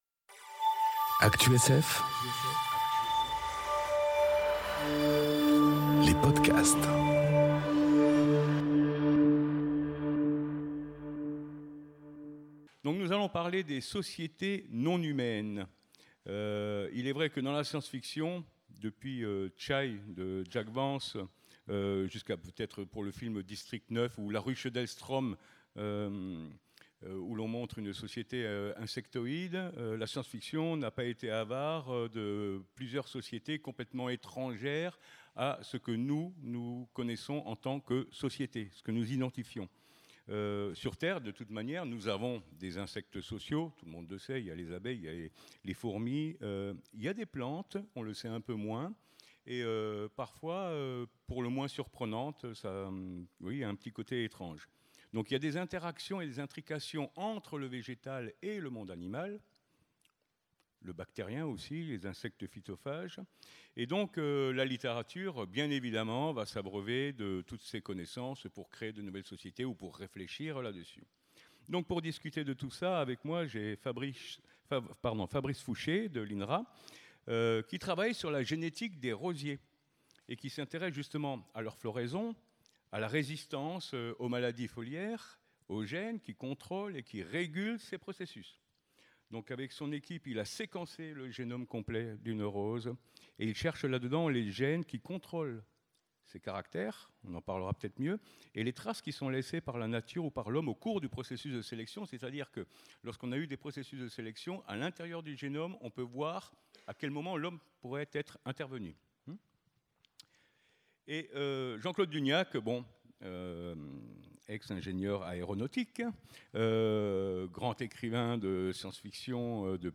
réécoutez la conférence Les sociétés non-humaines .